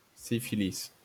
IPA/si.fi.lis/